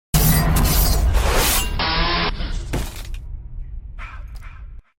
Slasher Gashing Wound Sound Button - Free Download & Play
Games Soundboard3 views